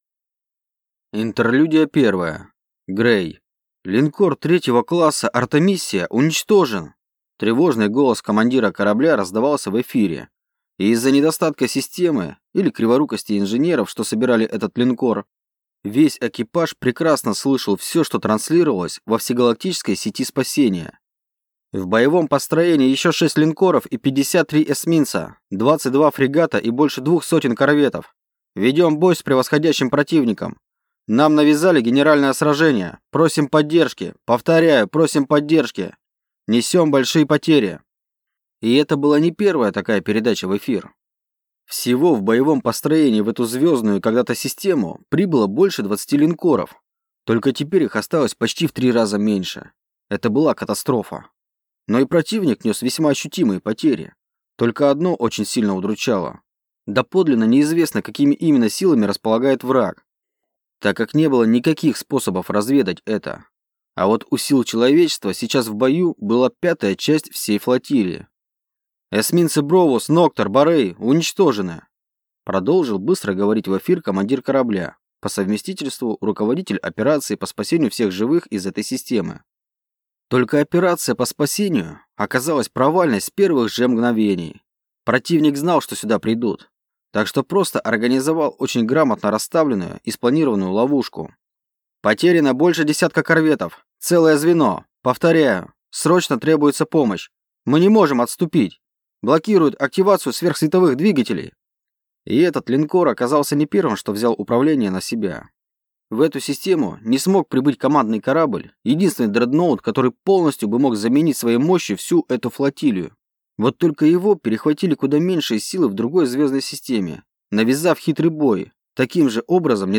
Аудиокнига Войд. Том 3 | Библиотека аудиокниг